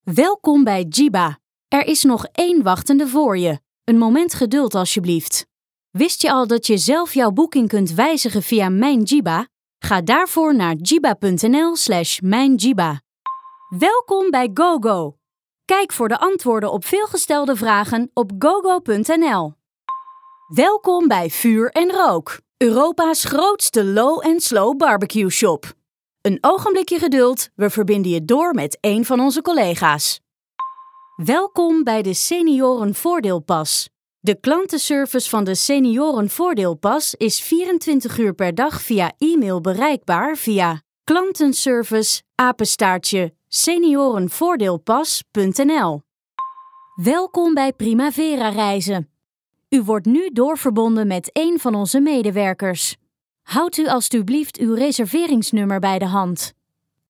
IVR
My voice sounds young, fresh and enthusiastic, but reliable.
Mic: Sennheiser MKH416